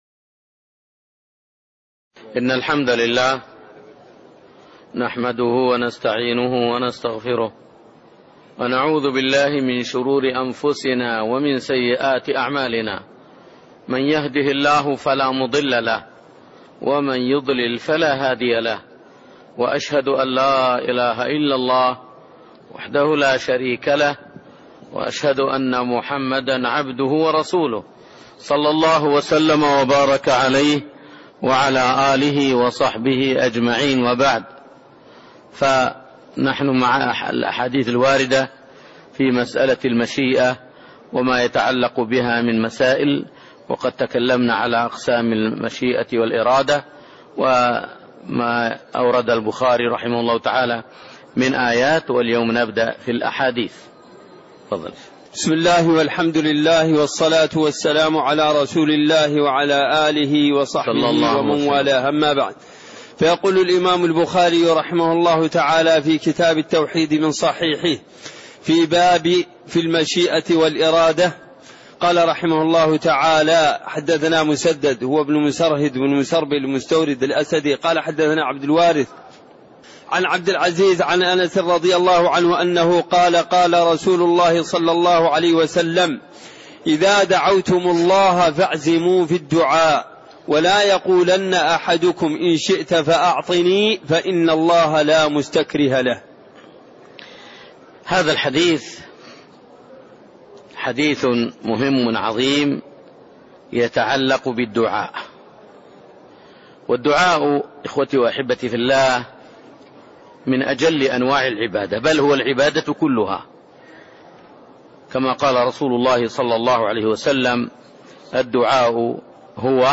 تاريخ النشر ٣٠ محرم ١٤٣٥ هـ المكان: المسجد النبوي الشيخ